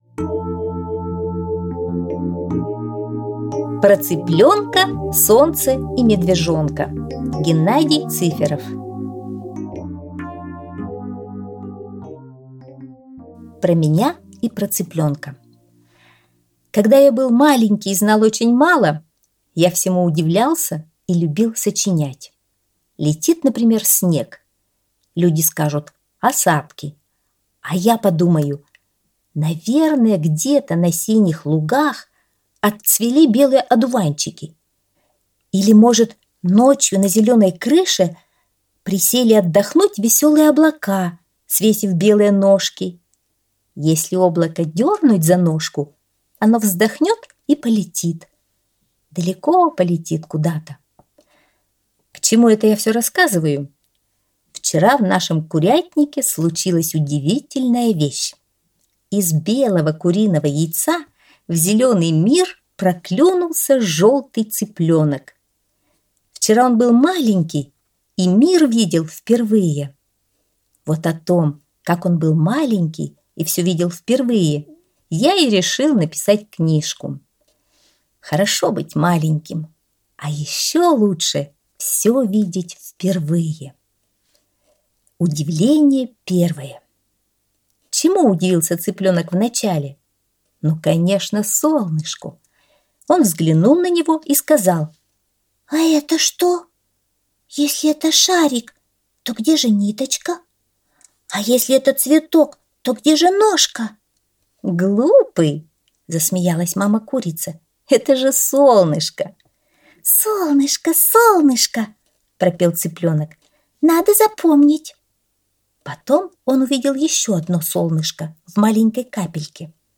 Про цыплёнка, солнце и медвежонка - аудиосказка Геннадия Цыферова - слушать онлайн